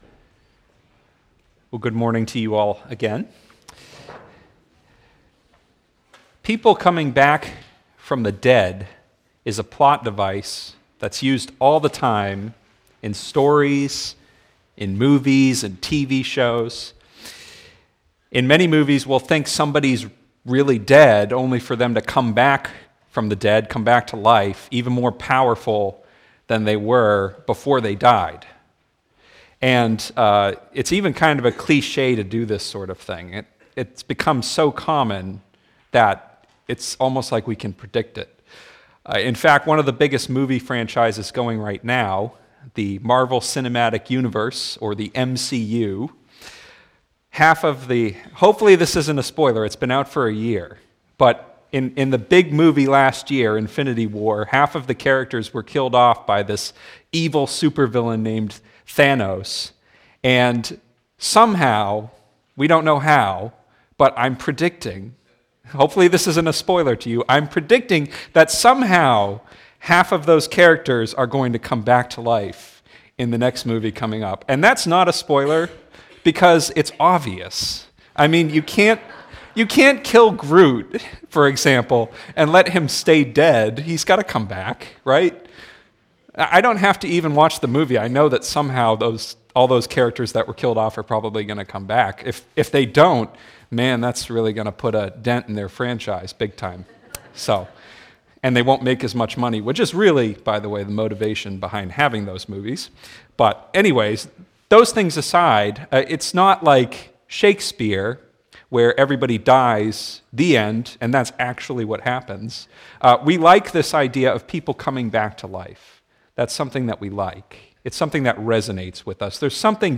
Sermons - Immanuel Church